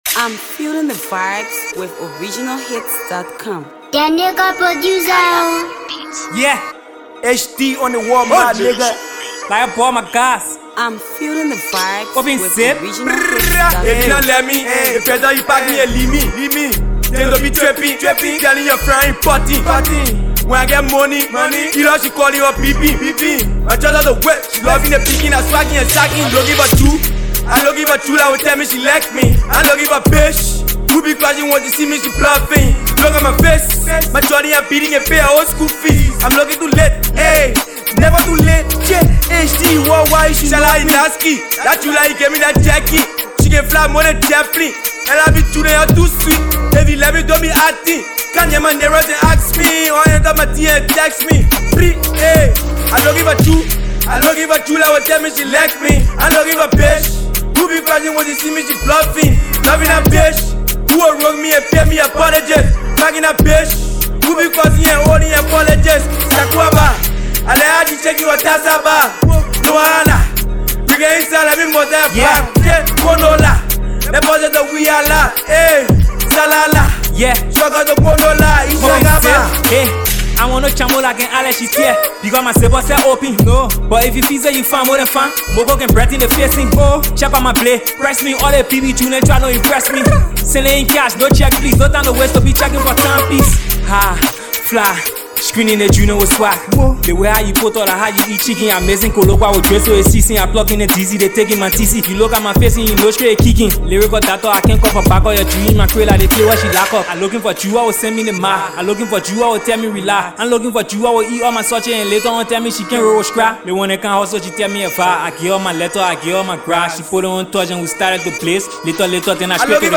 Multi-talented uprising trap artist